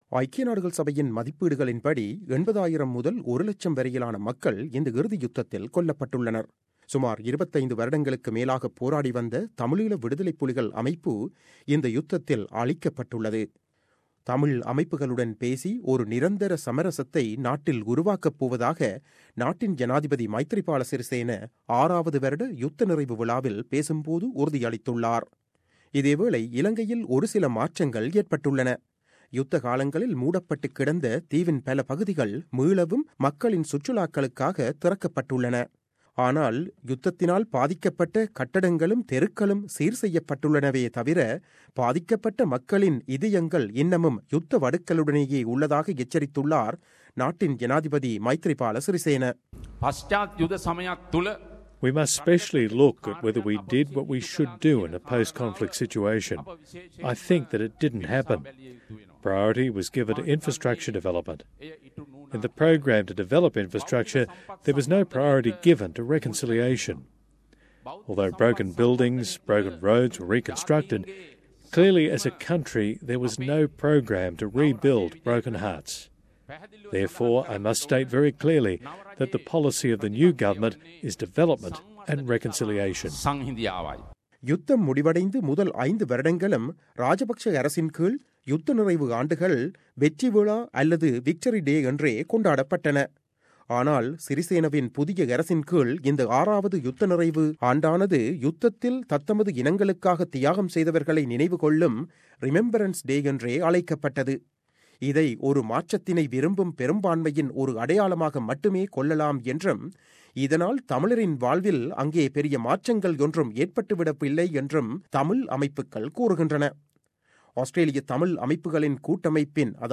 செய்தி விவரணம்